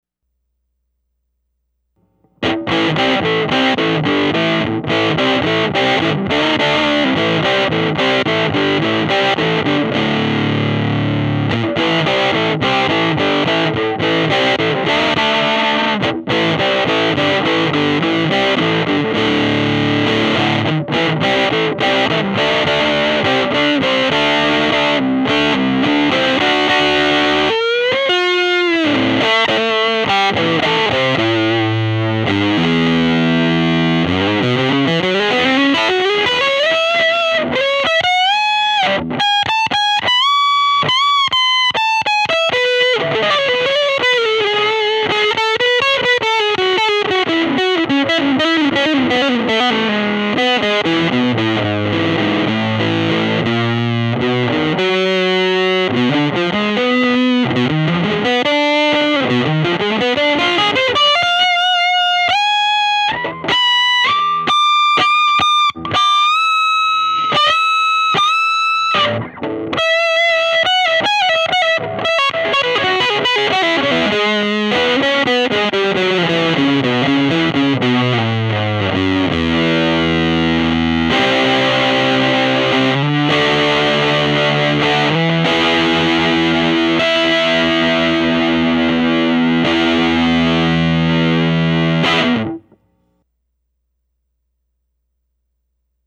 Treble on 8 .... bright switch off ... bass on 6 mids on 6 ......... The little pico sounded decent and it was really quiet for being dimed.